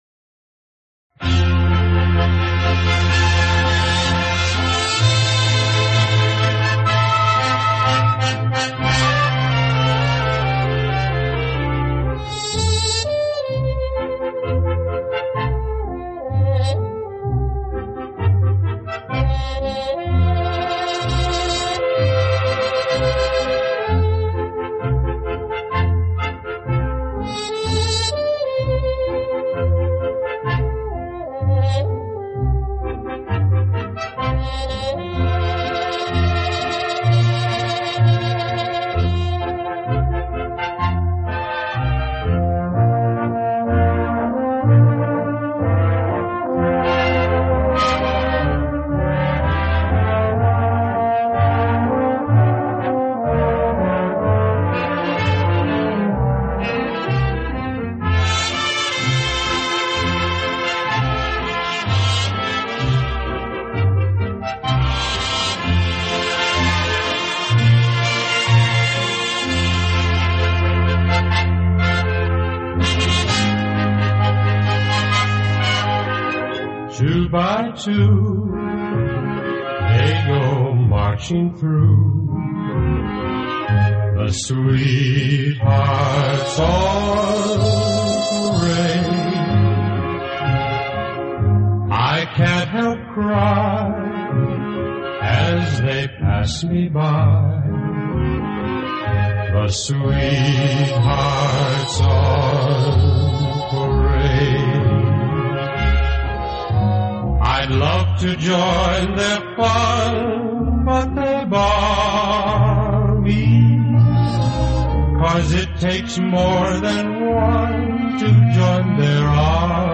the smooth vibrato of the saxophones